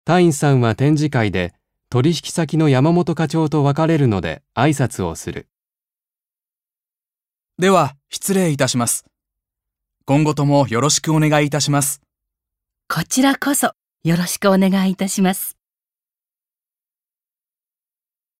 1.1. 会話（社外の人との挨拶あいさつ